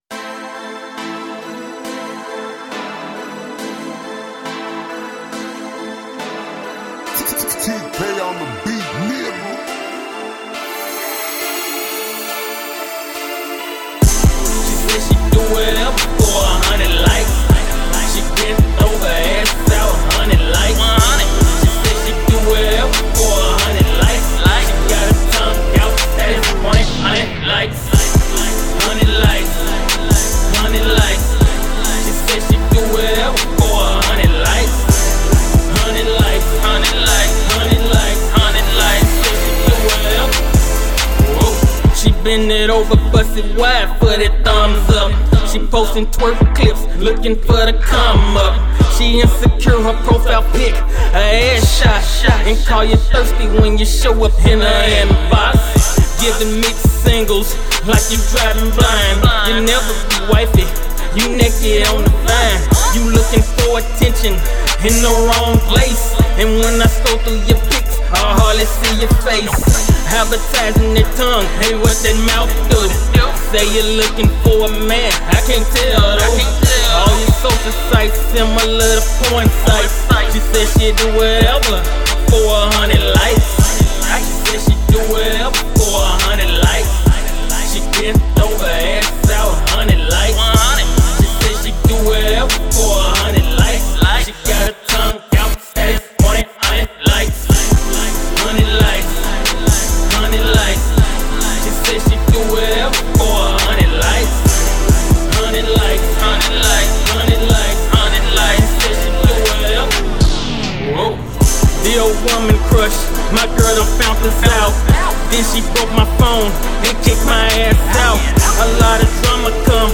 Description : Uptempo Club Track Prod.